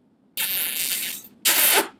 kiss.wav